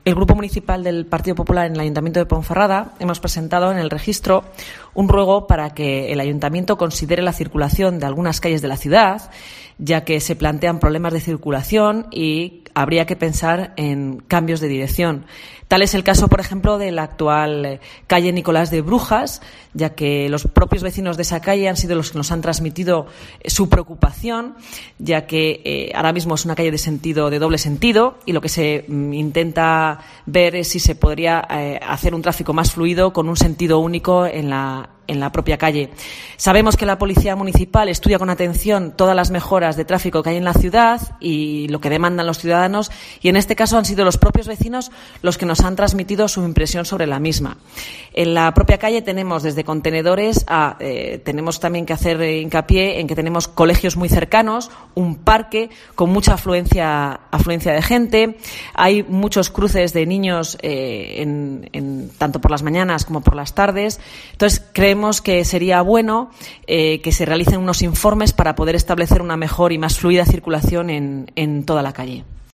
AUDIO: Escucha aquí a la concejal 'popular' en la capital berciana Rosa Luna